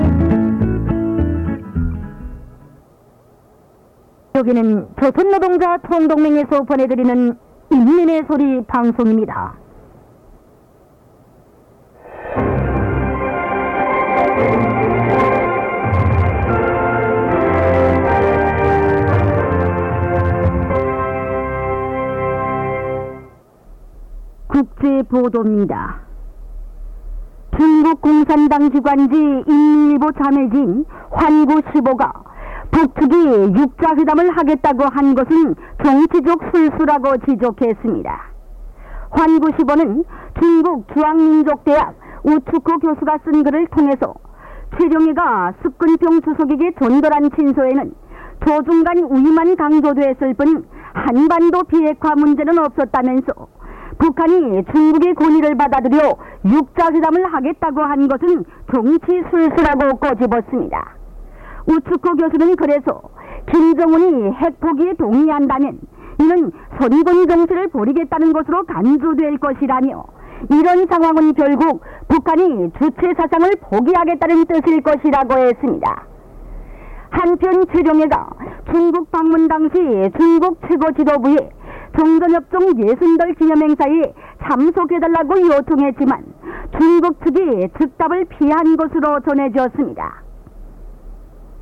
But close to this mystery transmitter site, the North Korean jamming signal cannot be heard over “Voice of the People.” The signal of the South Korean station is strong and clear. It’s so strong, it overloaded my radio: